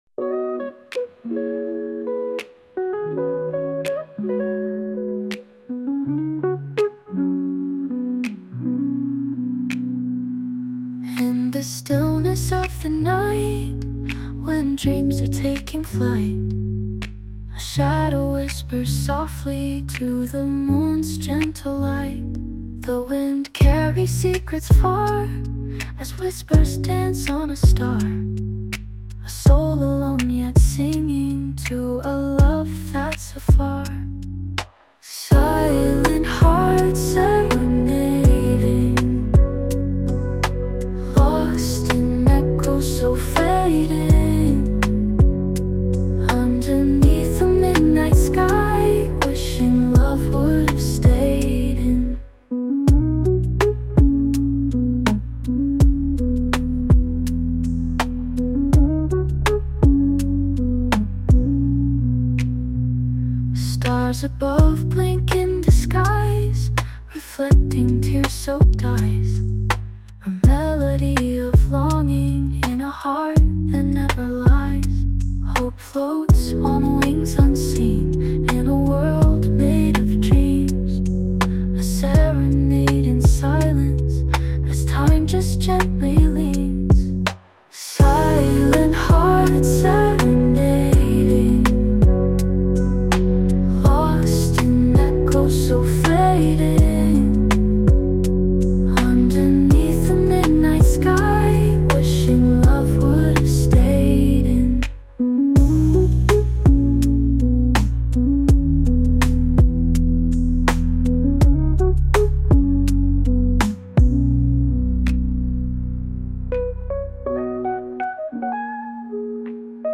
☕ Coffee Break: Create a cozy café atmosphere at home.